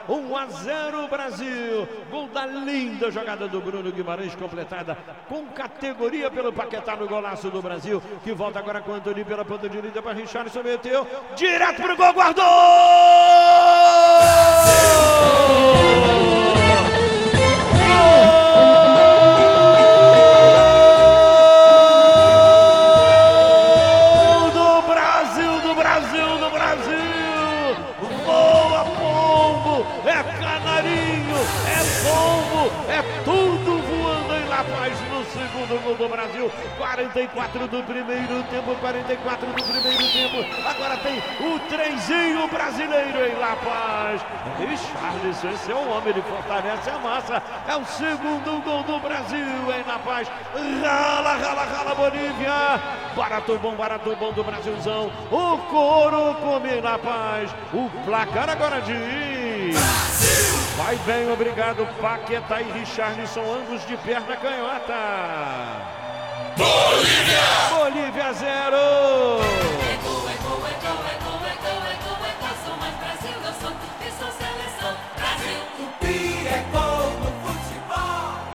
Ouça os gols da vitória do Brasil sobre a Bolívia com a narração de Luiz Penido